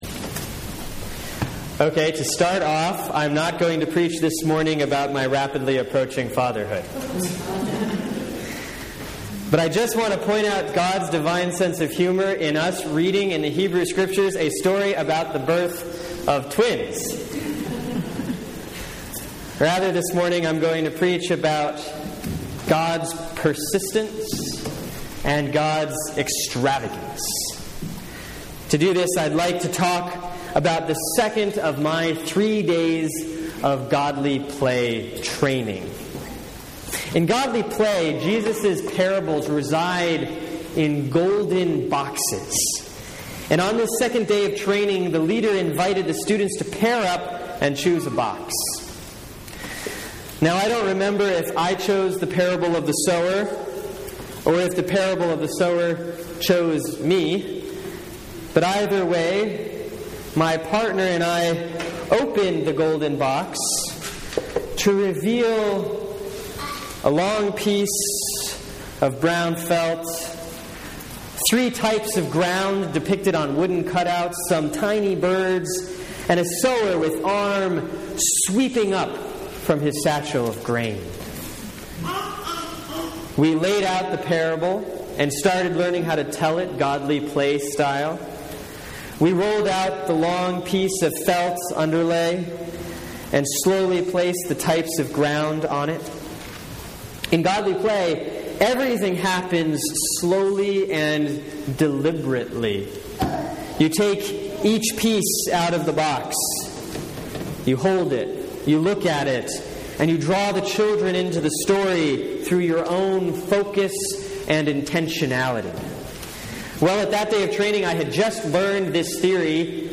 Sermon for Sunday, July 13, 2014 || Proper 10A || Matthew 13:1-9, 18-23